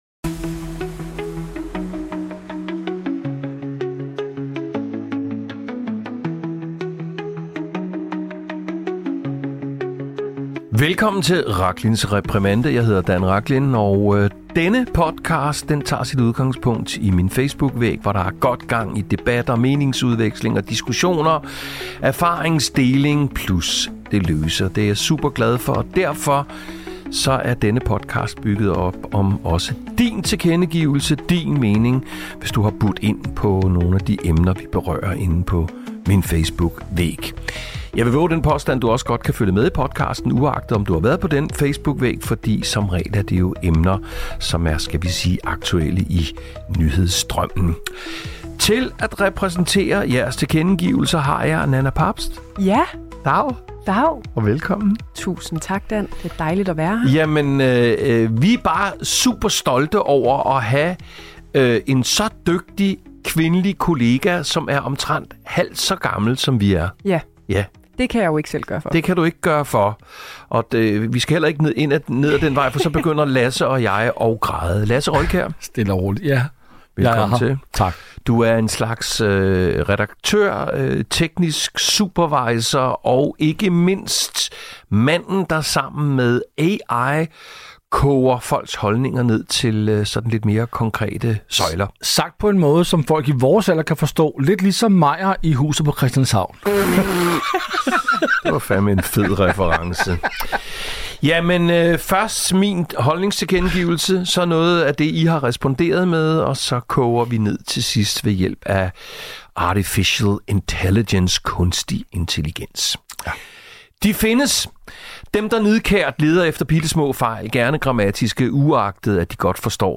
Der bliver både læst højt og læst op – af brok, varme hilsner og skarpe holdninger.